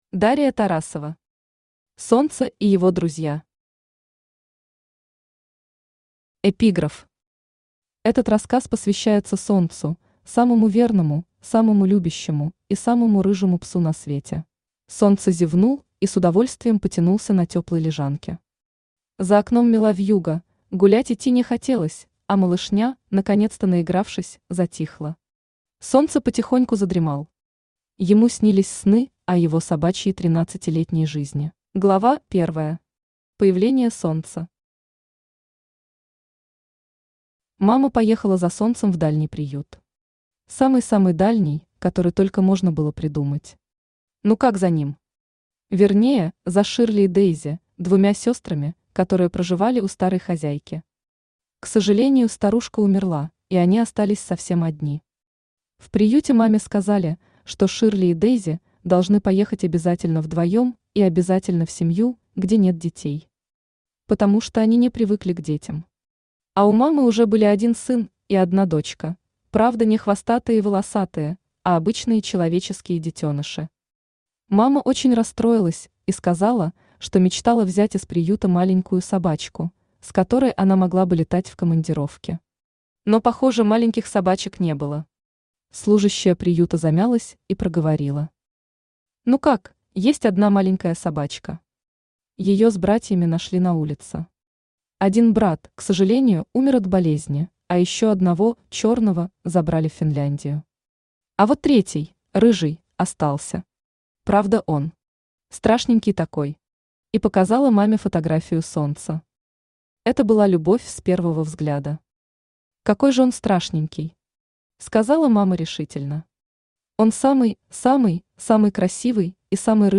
Аудиокнига Солнце и его друзья | Библиотека аудиокниг
Aудиокнига Солнце и его друзья Автор Дария Тарасова Читает аудиокнигу Авточтец ЛитРес.